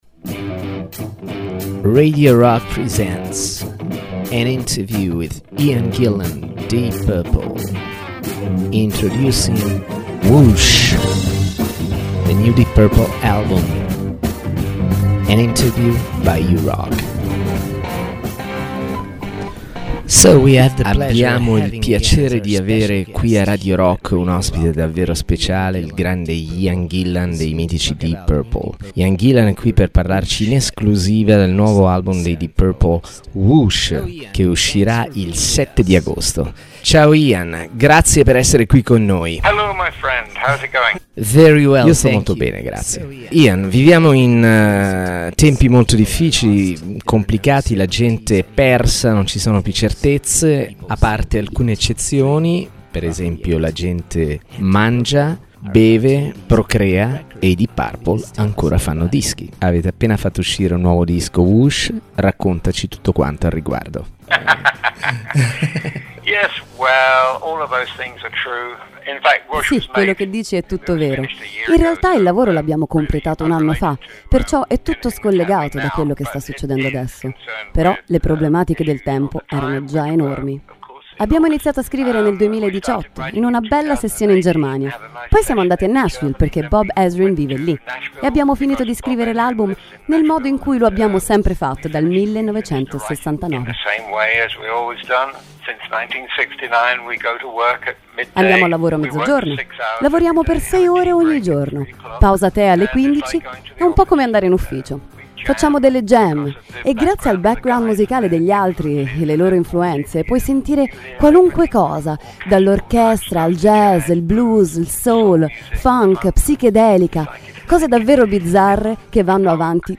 Intervista: "Ian Gillan - Deep Purple" (06-08-20)
Ian Gillan, leggendario cantante dei Deep Purple, parla in esclusiva ai microfoni di RadioRock, per presentare "Whoosh", il nuovo disco della band inglese, in uscita il 7 Agosto. Gli aneddoti e le storie dietro le canzoni, il segreto di come mantenere la voce in forma, le tematiche dei testi, e la ragione per cui Whoosh é un album diverso riguardo ai dischi precedenti dei Deep Purple.
IAN-GILLAN-INTERVISTA.mp3